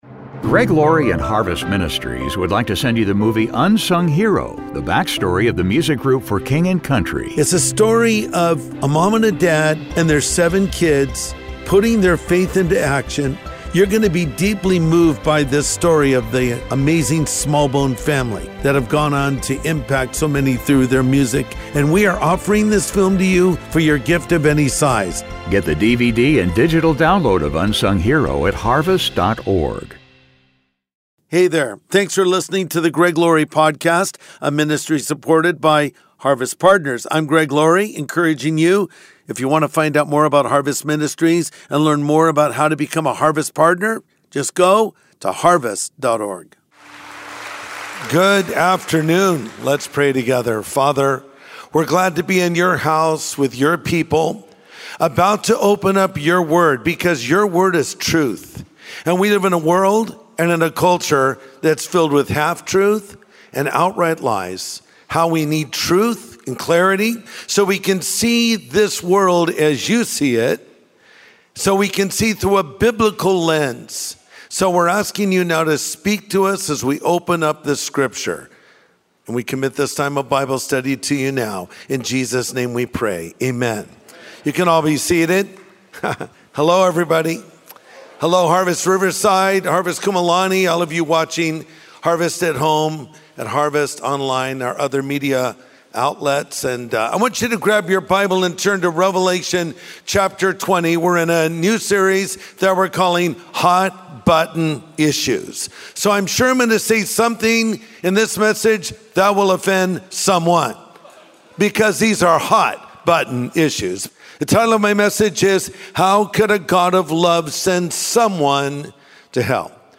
How Could a God of Love Send Someone to Hell? | Sunday Message